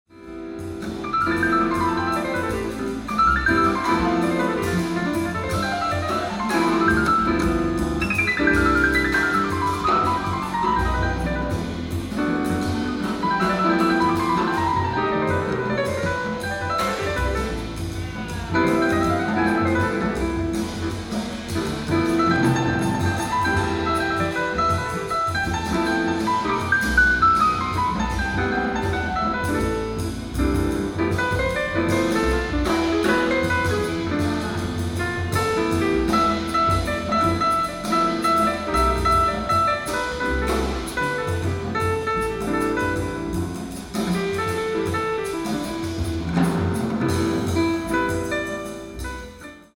ライブ・アット・渋谷公会堂、東京 03/20/1996
※試聴用に実際より音質を落としています。